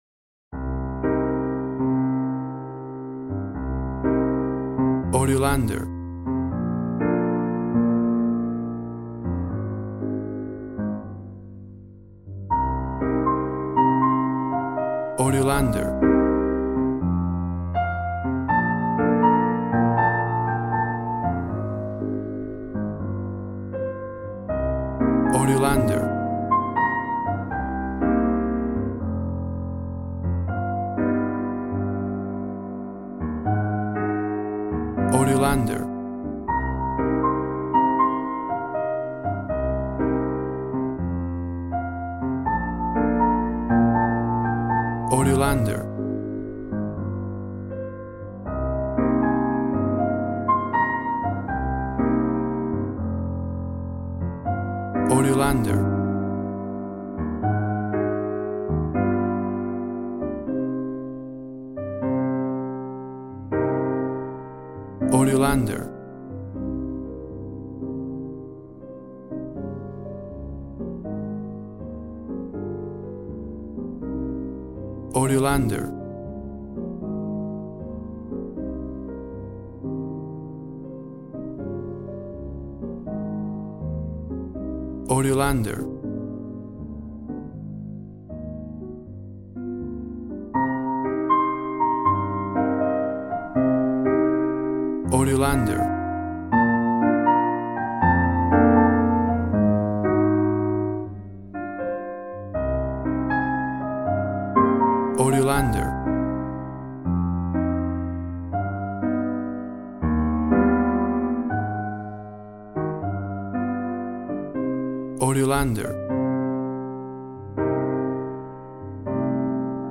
Smooth jazz piano mixed with jazz bass and cool jazz drums.
Tempo (BPM) 78